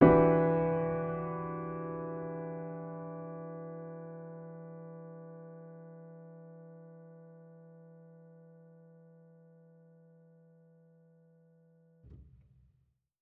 Index of /musicradar/jazz-keys-samples/Chord Hits/Acoustic Piano 2
JK_AcPiano2_Chord-Em9.wav